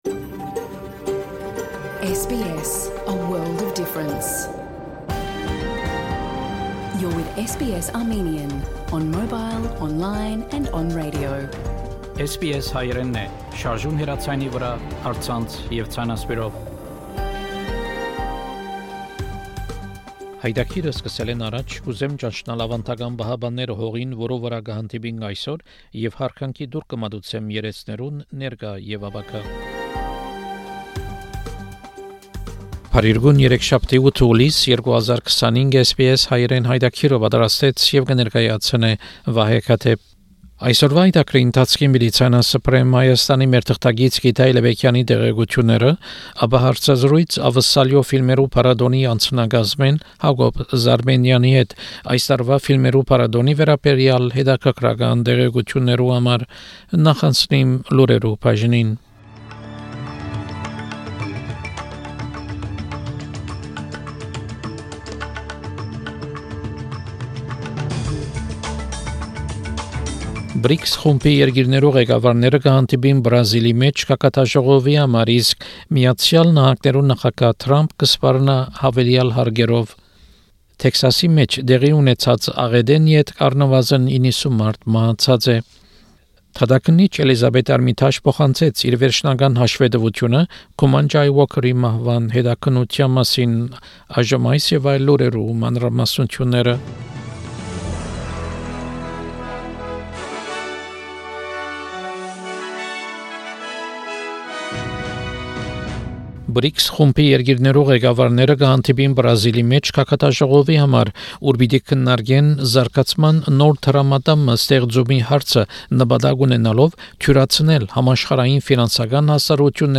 SBS Armenian news bulletin from 8 July 2025 program.